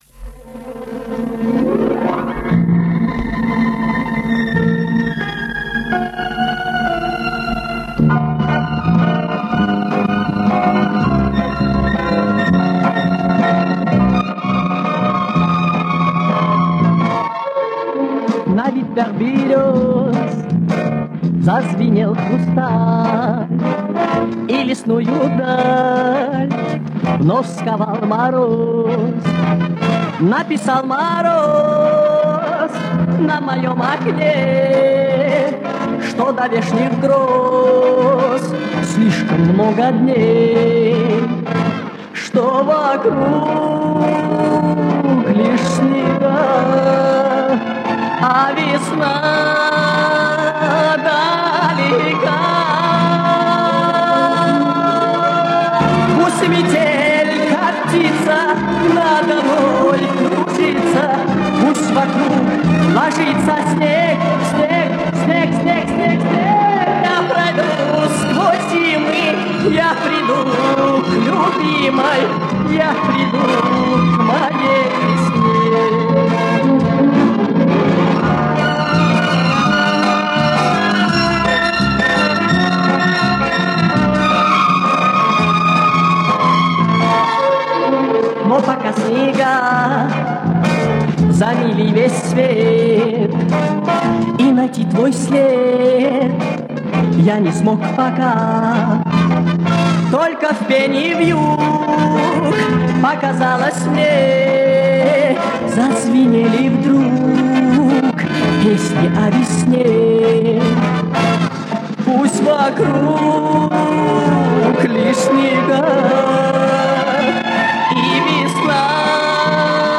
видимо, такого качества запись ощутимо выровнять нереально.